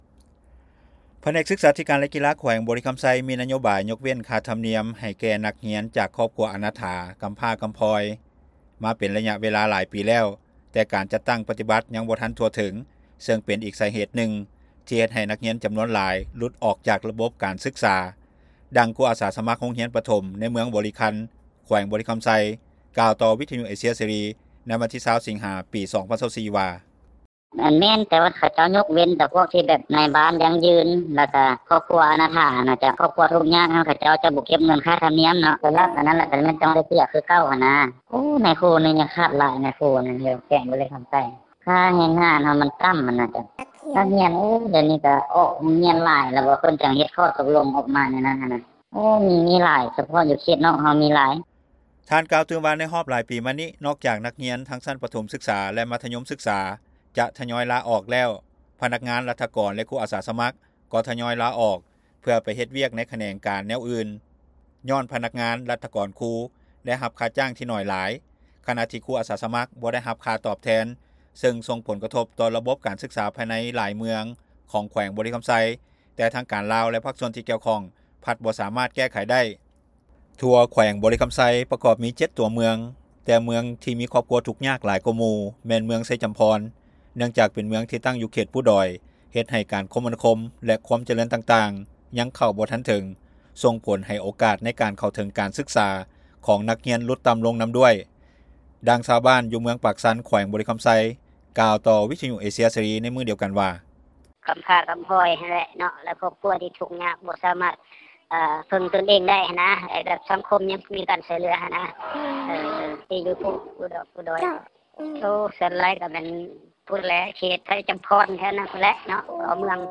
ດັ່ງຊາວບ້ານເມືອງປາກຊັນ ແຂວງບໍລິຄຳໄຊ ກ່າວຕໍ່ວິທຍຸເອເຊັຍເສຣີໃນມື້ດຽວກັນວ່າ.